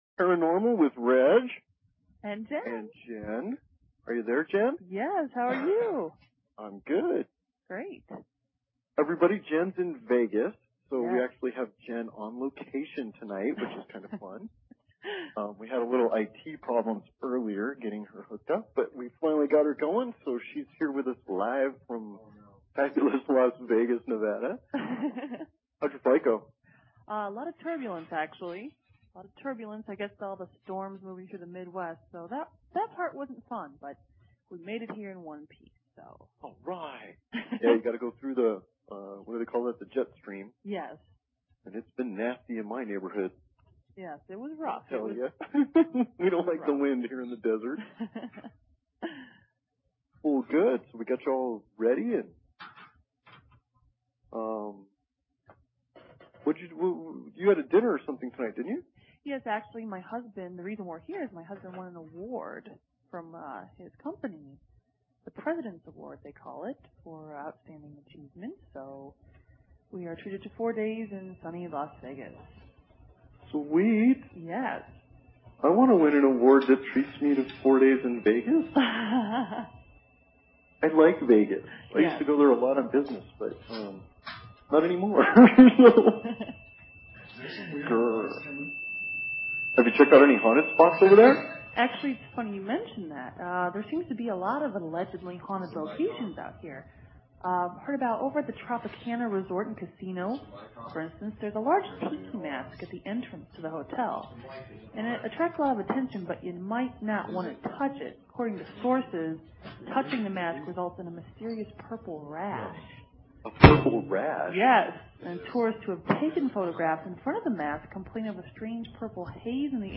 Evening Trial, a talk show on BBS Radio!